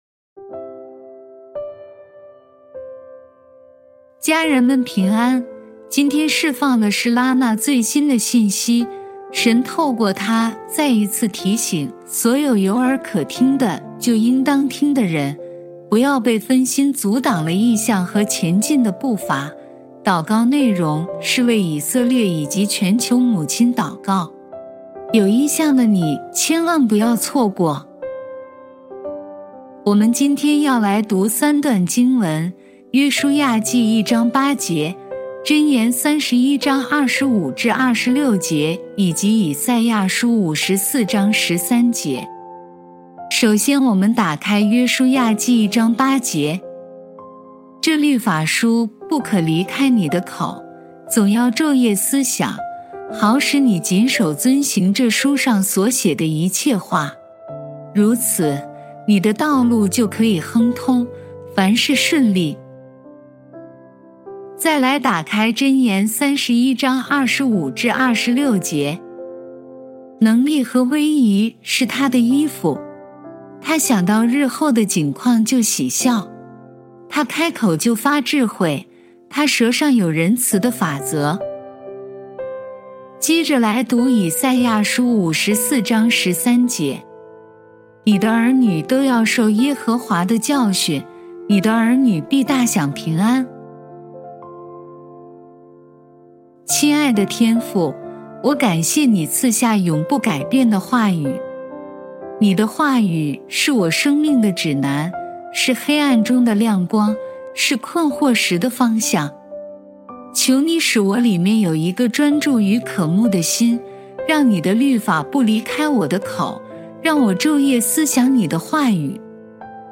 并以第一人称为大家朗读